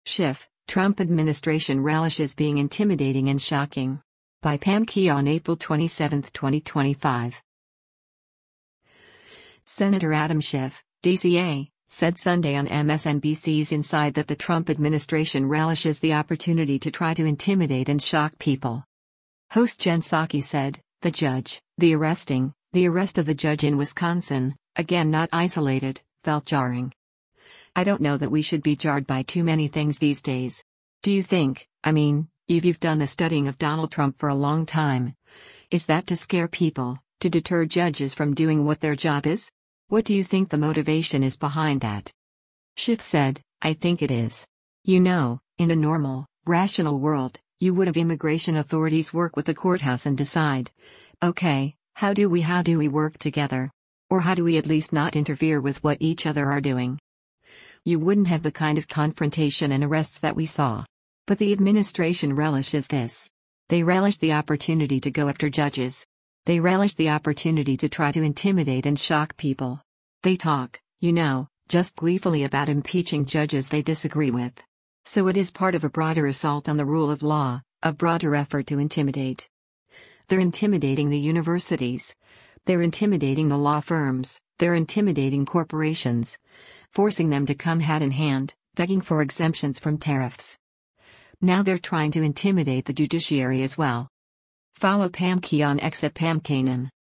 Senator Adam Schiff (D-CA) said Sunday on MSNBC’s “Inside” that the Trump administration “relishes the opportunity to try to intimidate and shock people.”